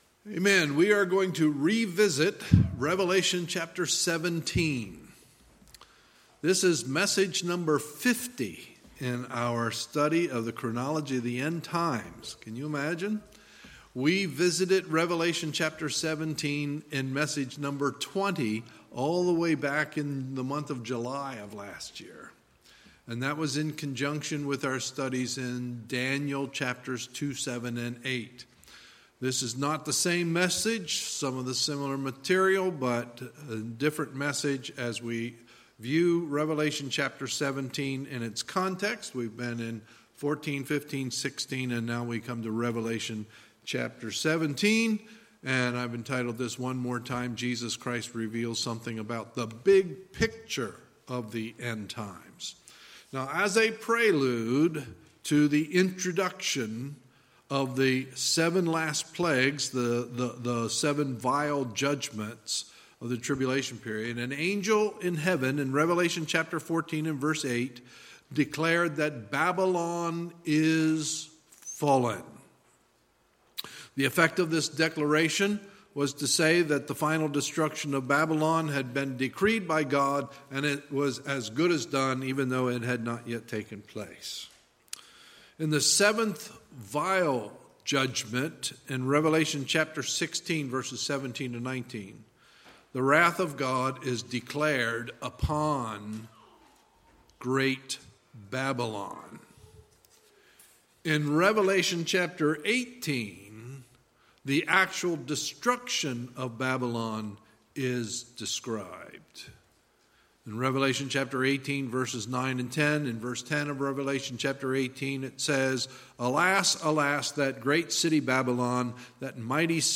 Sunday, May 12, 2019 – Sunday Evening Service
Sermons